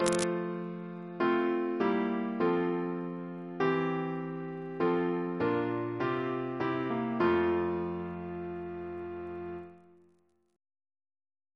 Single chant in F Composer: Rt Rev Thomas Turton (1780-1864), Dean of Peterborough and Westminster, Bishop of Ely Reference psalters: ACB: 48; PP/SNCB: 95